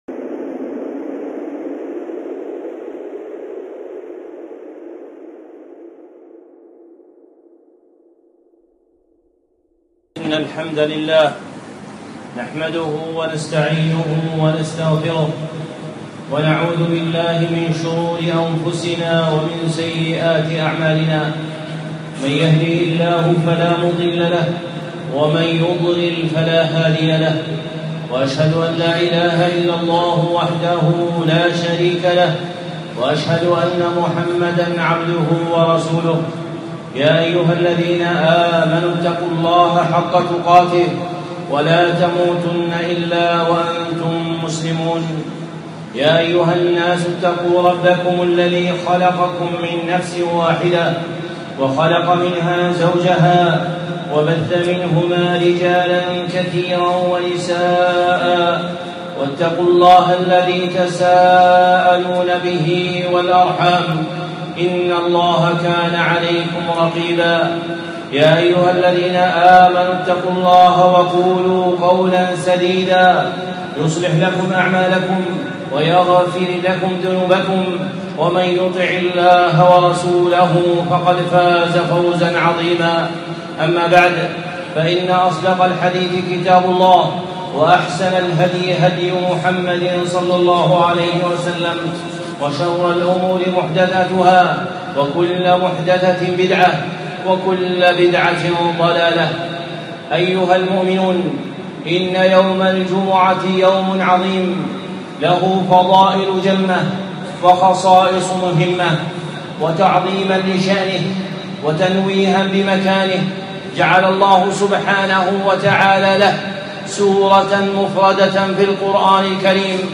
خطبة (فضائل الجمعة) الشيخ صالح العصيمي